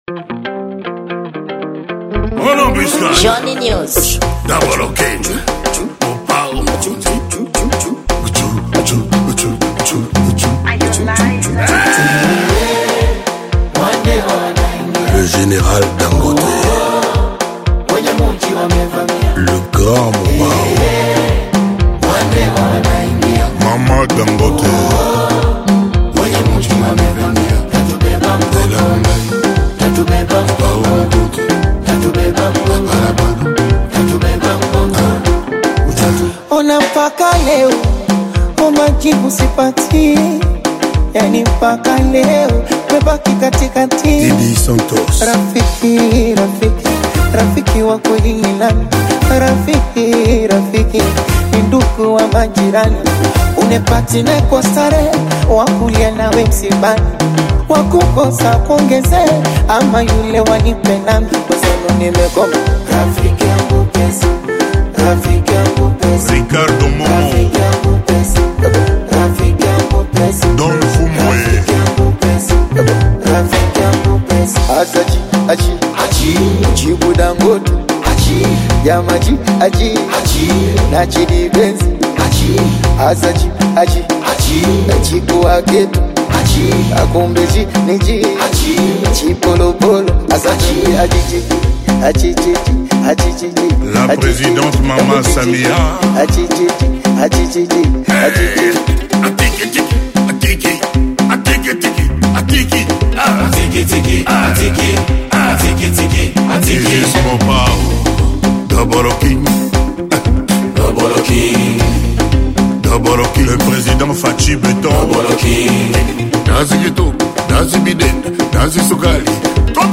Gênero: Afro Congo